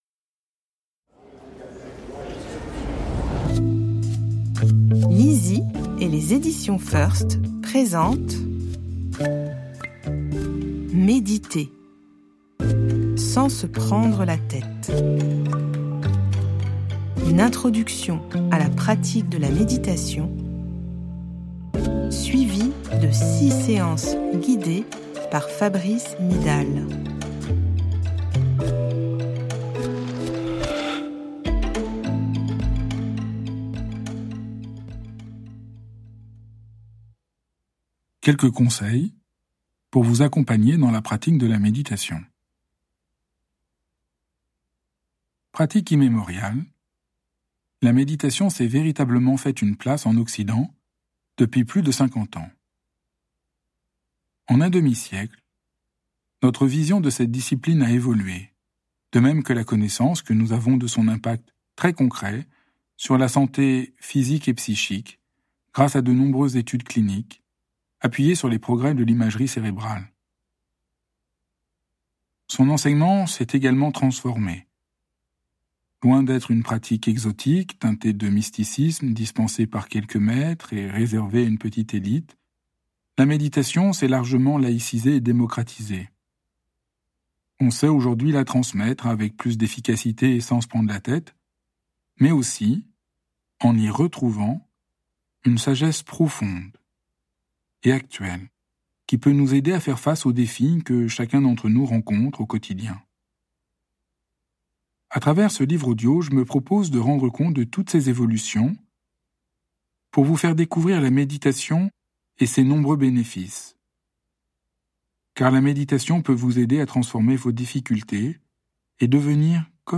Extrait gratuit - Méditer sans se prendre la tête - Par l'auteur du best-seller " Foutez-vous la paix !